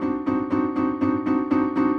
Index of /musicradar/gangster-sting-samples/120bpm Loops
GS_Piano_120-D2.wav